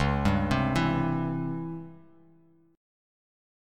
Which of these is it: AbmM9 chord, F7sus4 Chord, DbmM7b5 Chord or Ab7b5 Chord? DbmM7b5 Chord